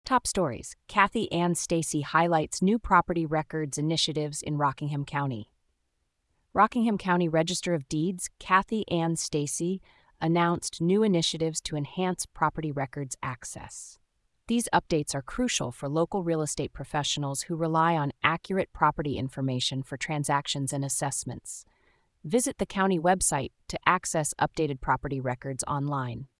Daily briefing for real estate professionals in New Hampshire